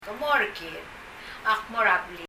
er a ・・・の e は音としてはほとんど消えていて、　er a blik [rə blik] と